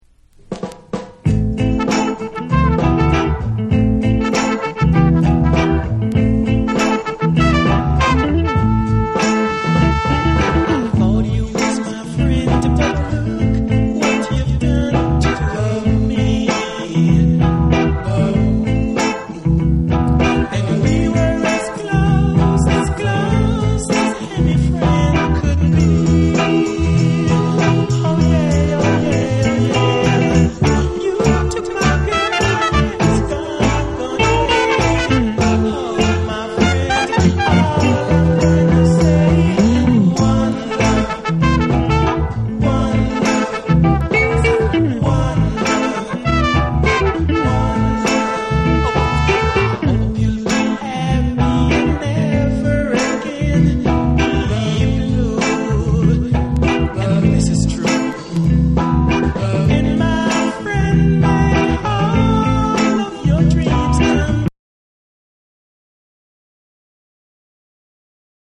（ジャマイカ盤につきチリノイズ多し）
REGGAE & DUB